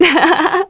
Juno-Vox_Happy1_kr.wav